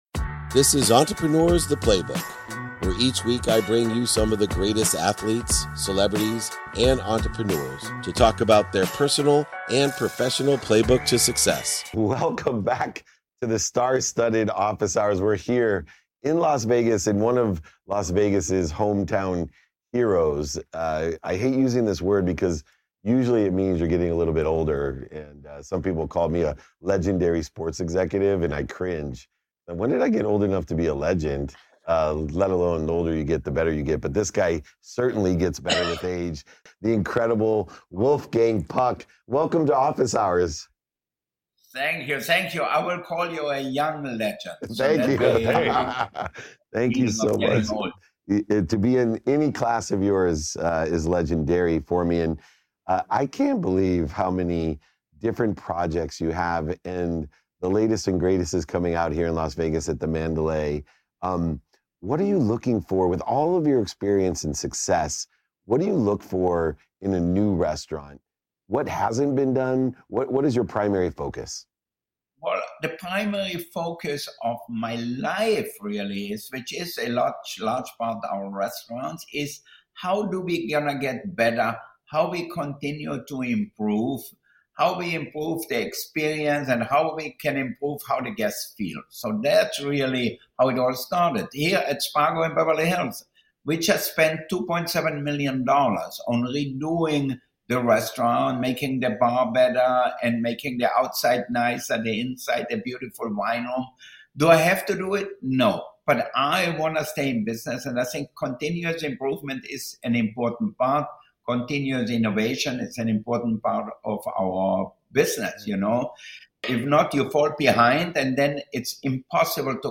In today’s episode, I'm joined by the legendary chef and restaurateur, Wolfgang Puck, in a conversation that spans the essence of innovation, the art of continuous improvement, and the power of treating people well within the business realm. Wolfgang shares the journey of enhancing guest experiences across his global restaurants, emphasizing the importance of a familial approach to team dynamics and the relentless pursuit of culinary excellence.